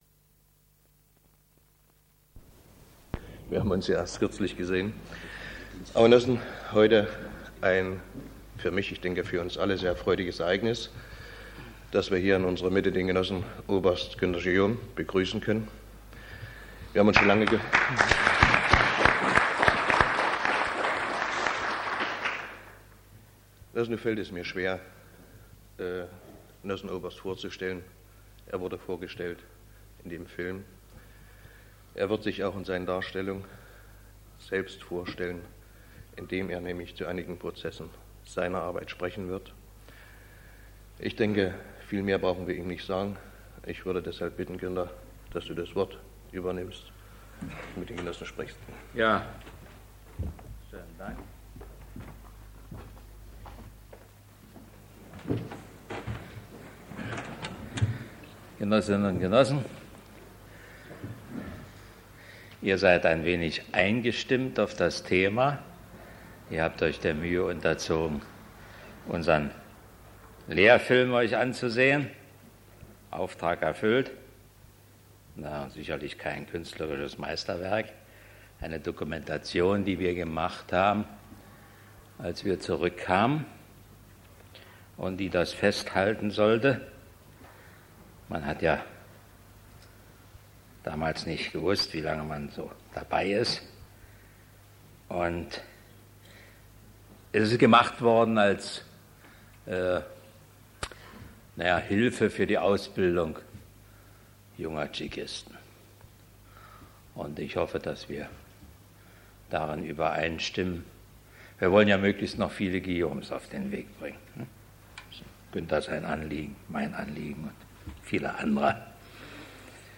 Am 29. März 1989 sprach der als Topspion gefeierte Guillaume vor Mitarbeitern der MfS-Bezirksverwaltung (BV) Rostock über seine Tätigkeit als "Kundschafter" in der Bundesrepublik.